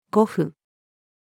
護符-female.mp3